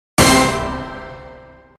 The sound that plays when keeping a combo in a race